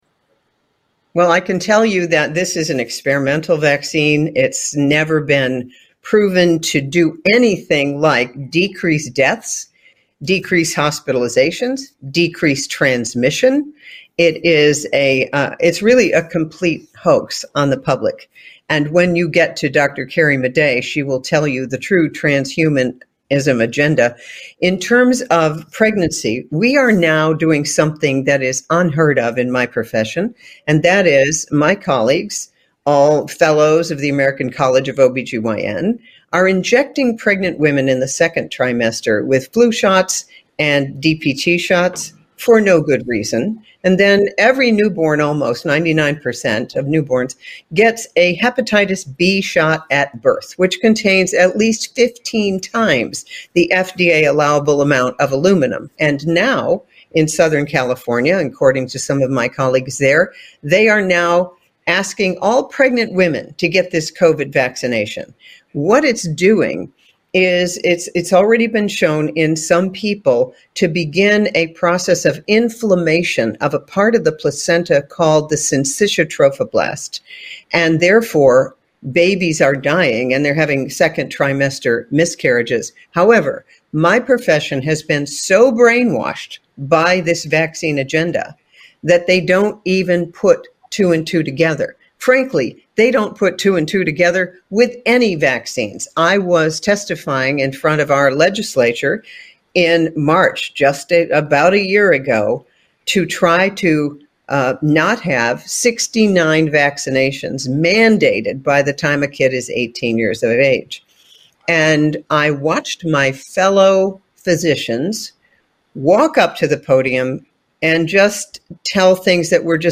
Northrup-interview-vaccine.mp3